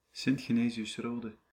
Sint-Genesius-Rode (Dutch: [sɪnt xeːˌneːzijʏs ˈroːdə]
Nl-Sint-Genesius-Rode.ogg.mp3